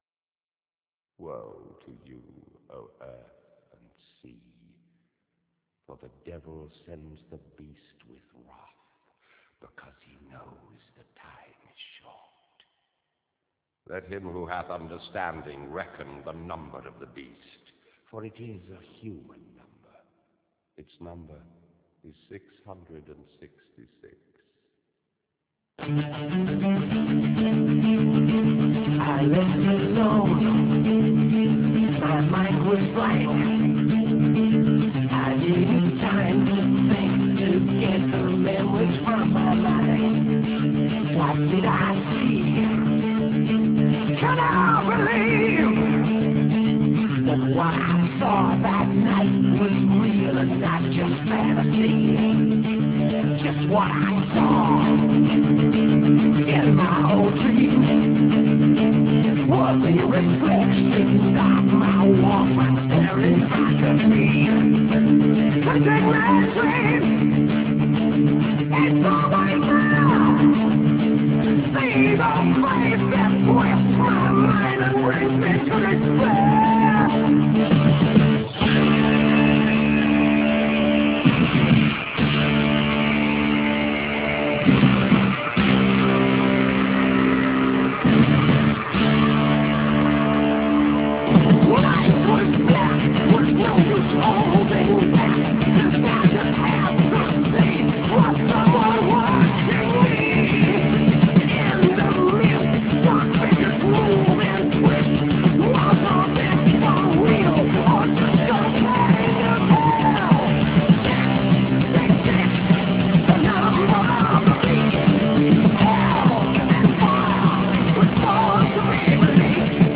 PRIMJER 1: Glazba sa CD-a
Low britaite voice (6.5Kb/s):